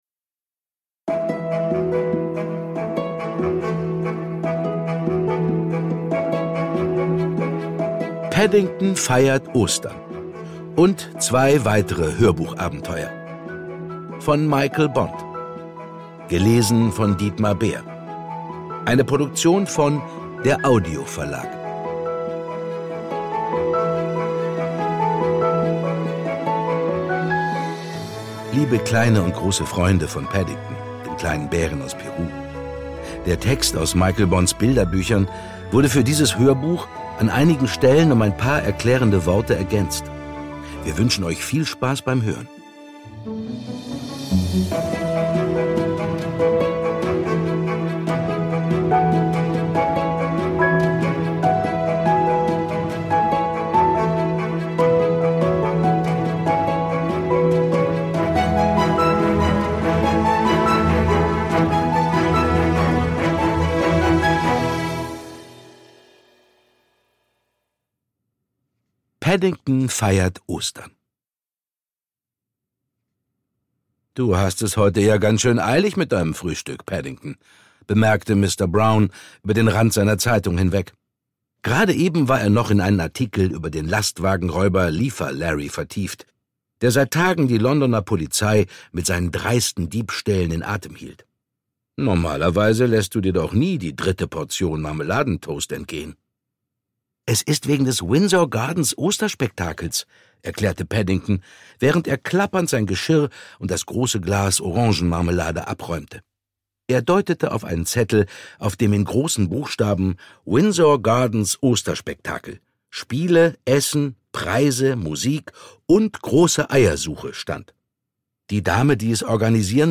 Ungekürzte Lesungen mit Musik mit Dietmar Bär (1 CD)
Dietmar Bär (Sprecher)